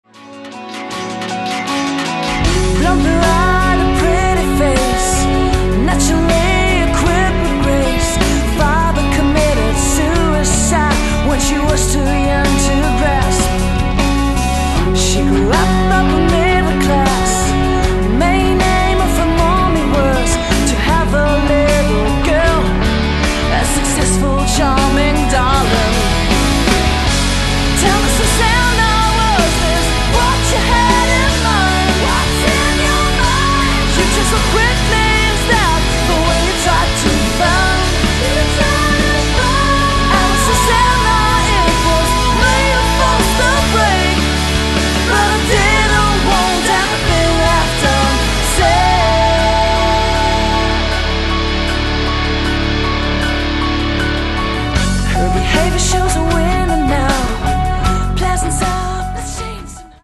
Genre: metal moderne